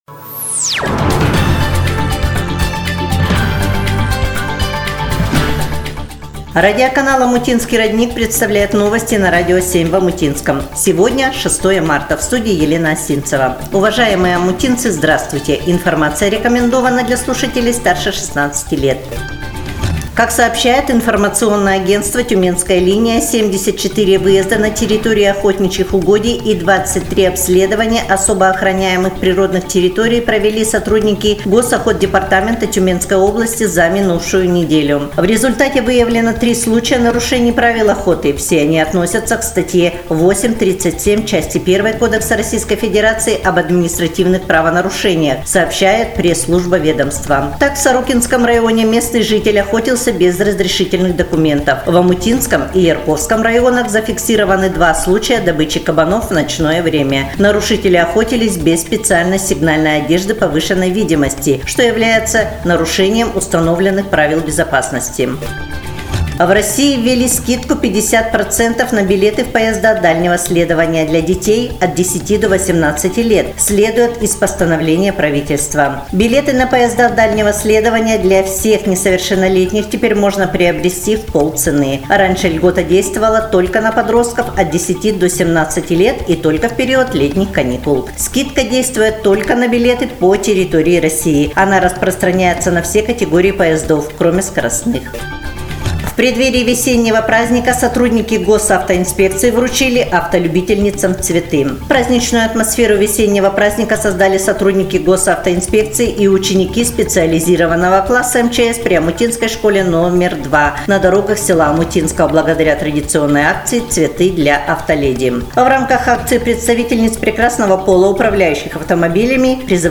Эфир радиоканала "Омутинский родник" от 6 Марта 2025 года